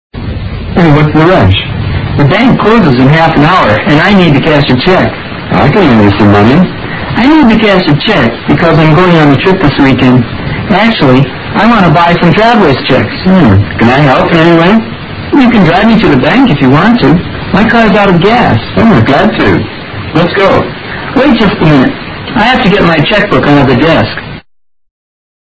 英语对话听力mp3下载Listen 14:CAN I HELP?
Dialogue 14